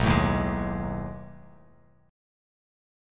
B13 Chord
Listen to B13 strummed